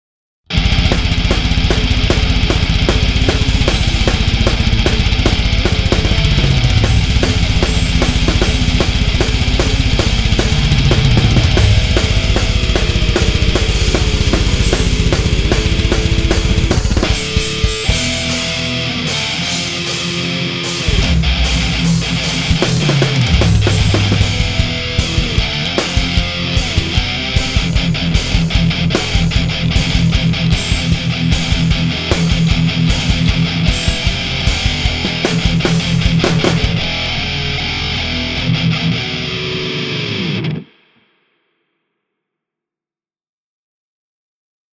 Habe mal je zwei Simulationen des Randall Amps im Amplitube versucht, Reverb drauf und beide links und rechts verteilt. Drums sind vorgefertigte Blöcke.